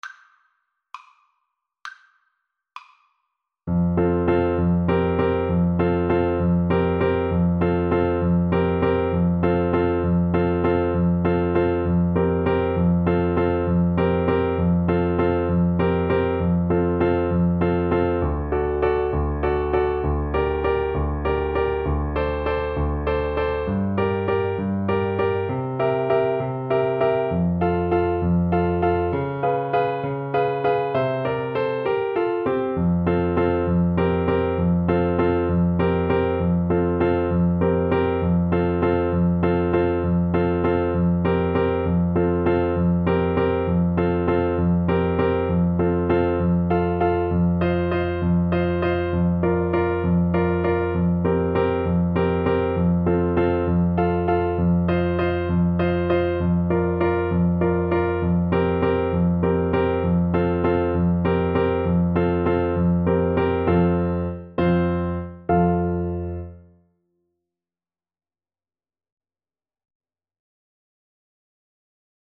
Play (or use space bar on your keyboard) Pause Music Playalong - Piano Accompaniment Playalong Band Accompaniment not yet available transpose reset tempo print settings full screen
F major (Sounding Pitch) G major (Trumpet in Bb) (View more F major Music for Trumpet )
. = 66 No. 3 Grazioso
6/8 (View more 6/8 Music)
Classical (View more Classical Trumpet Music)